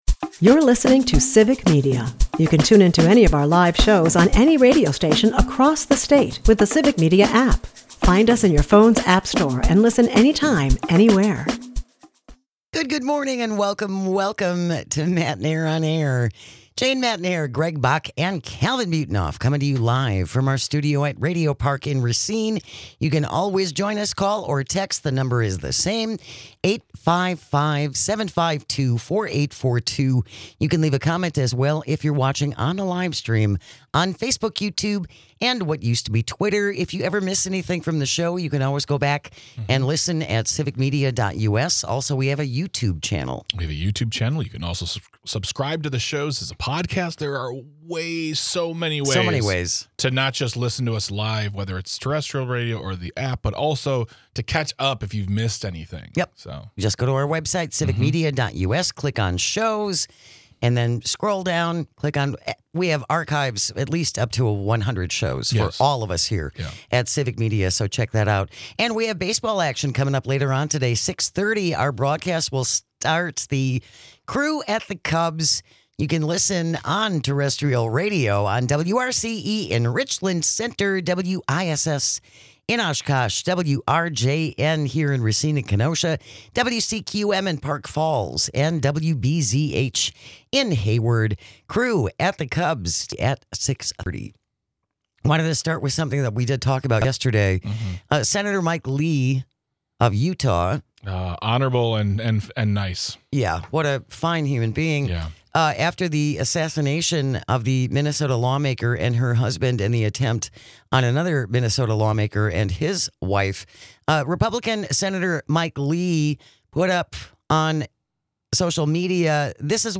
We hear from you on your favorite programs throughout the years. And we always celebrate the end of a great show with This Shouldn't Be A Thing - One Toke Over The Line Edition Matenaer On Air is a part of the Civic Media radio network and airs Monday through Friday from 9 -11 am across the state.